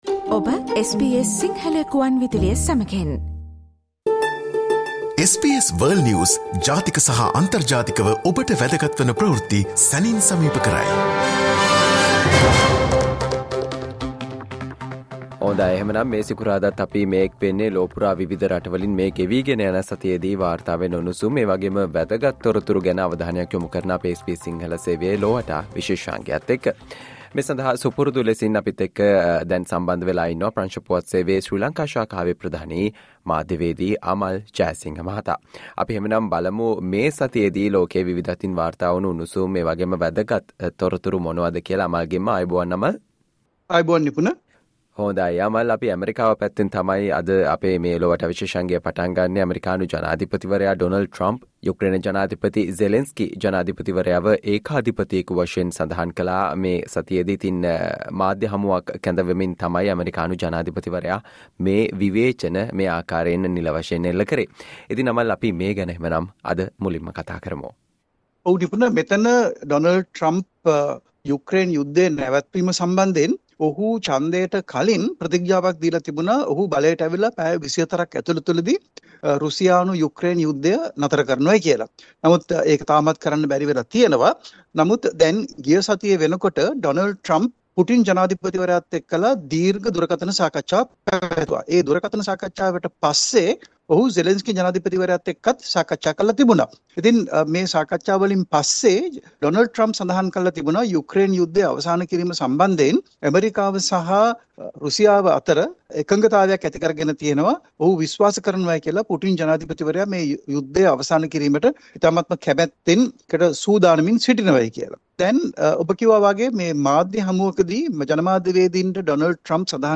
Zelensky escalated war over Russia, says Trump: World news wrap